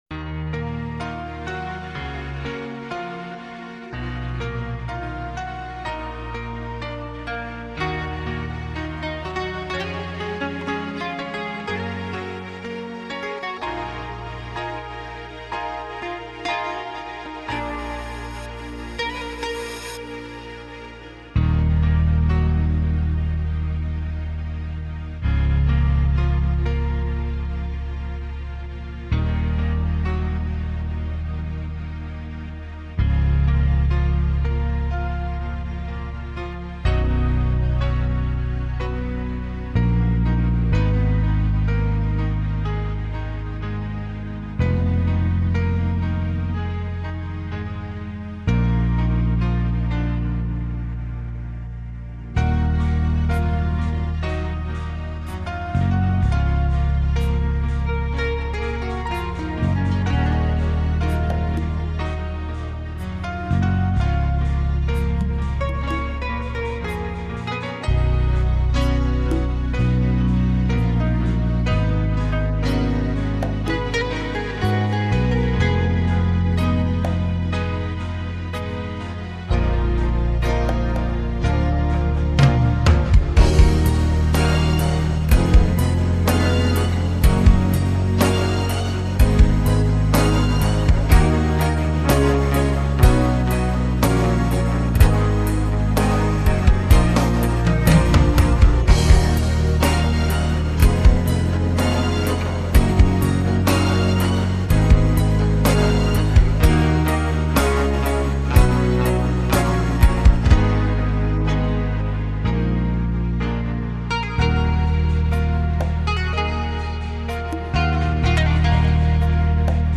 (минусовка версия 115299)